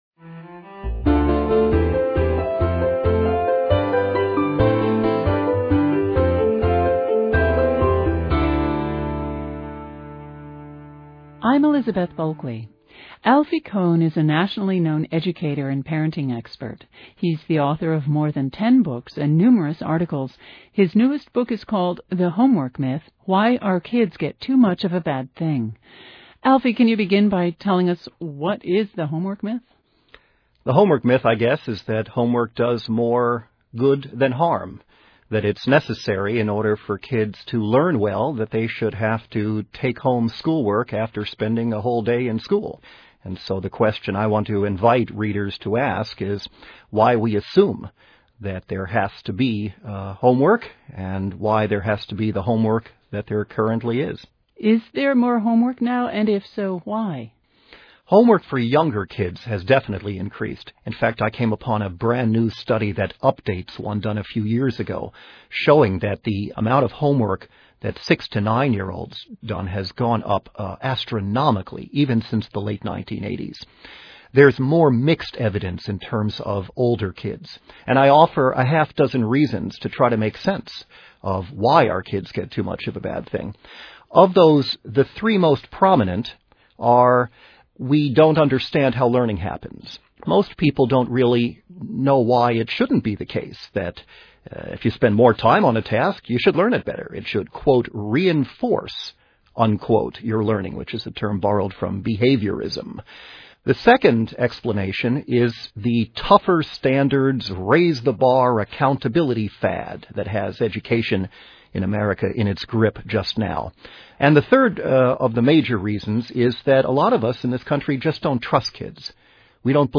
AUDIO INTERVIEWS OF ALFIE KOHN Interview # 1 Click on the link below to listen to a 10 minute audio interview with Alvie Kohn about this book.